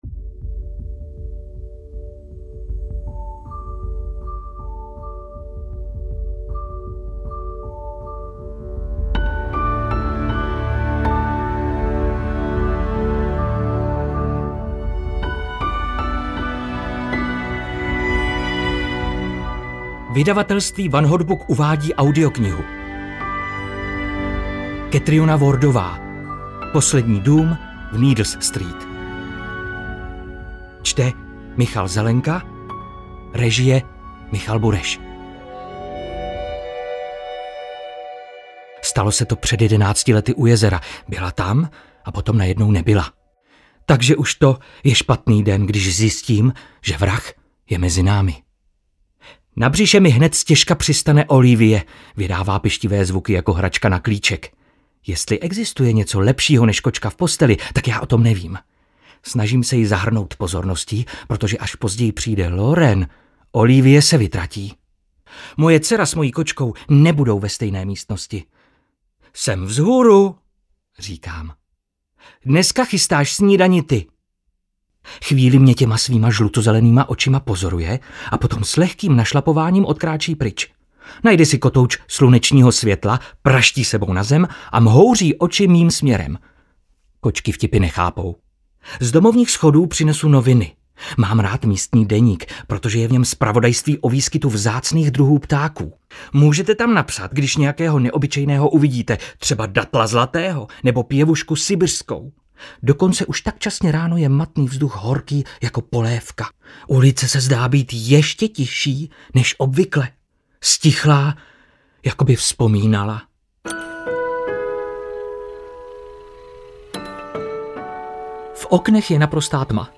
Poslední dům v Needless Street audiokniha
Ukázka z knihy
posledni-dum-v-needless-street-audiokniha